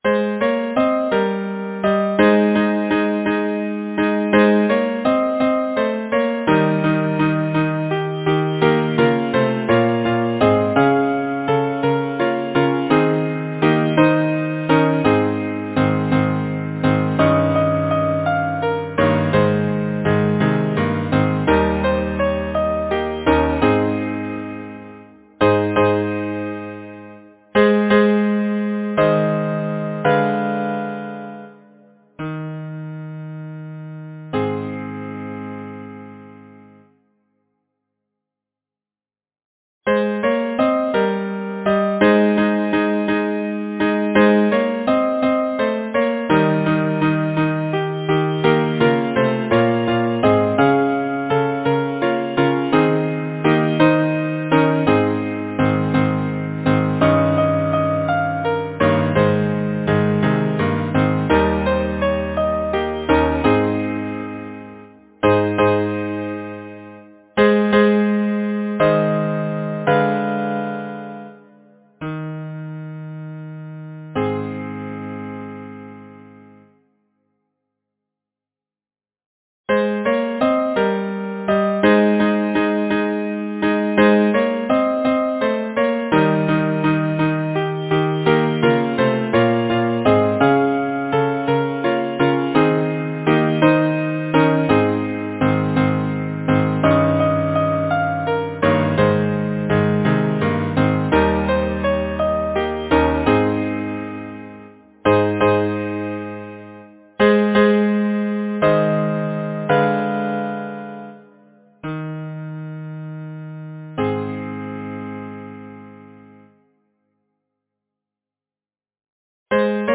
Number of voices: 4vv Voicings: SATB or ATTB Genre: Secular, Partsong
Language: English Instruments: Piano